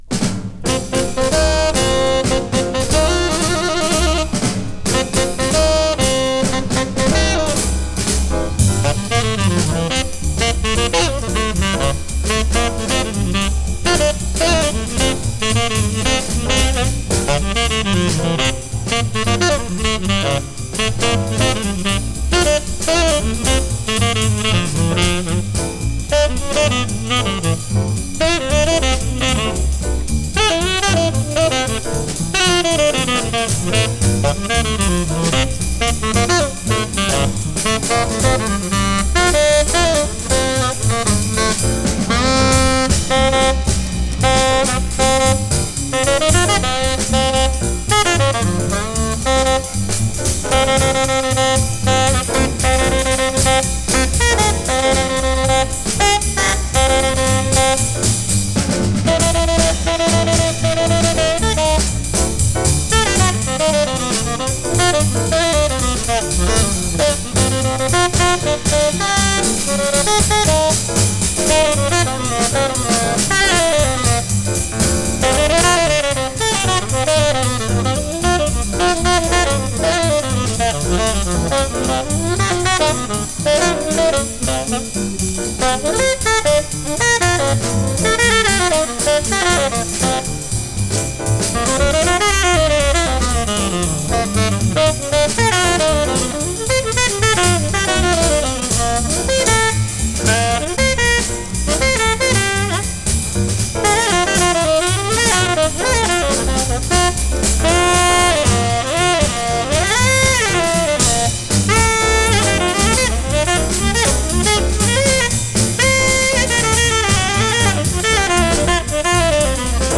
Recorded: 23 May, 1963 in Paris, France
Tenor Sax
Piano
Bass
Drums
The results are a free-wheeling, happy session of jazz.